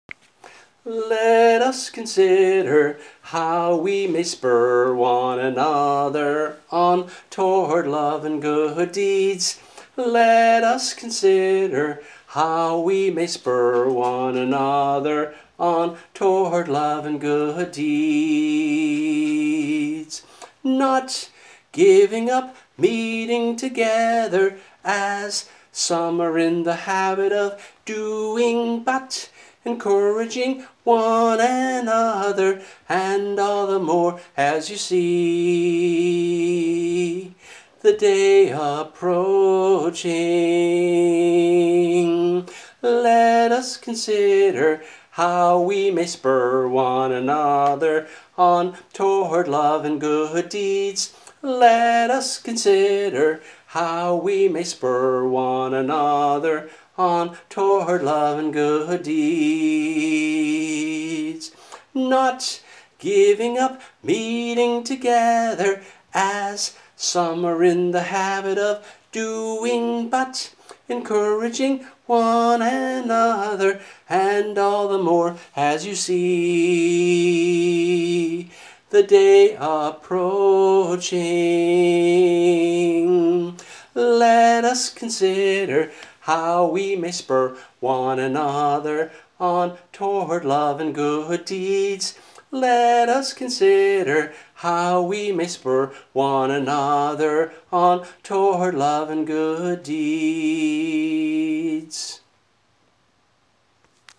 voice only